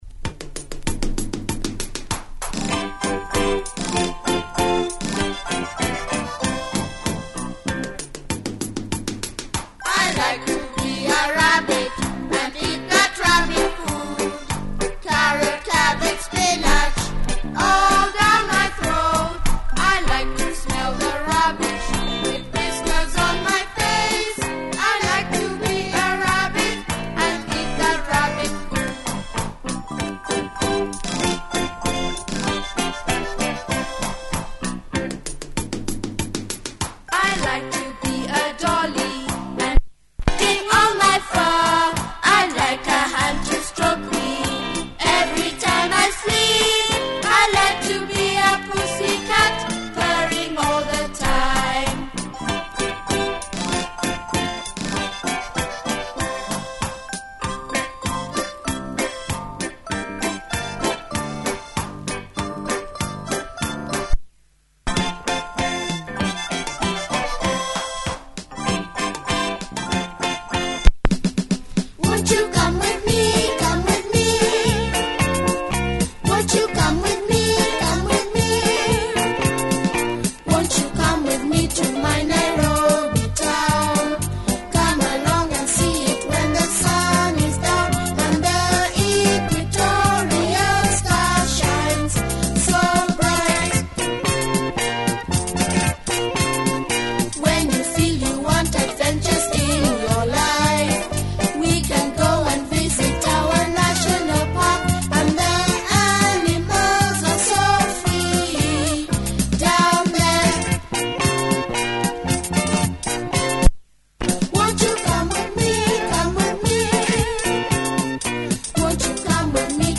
Kiddie music from Kenya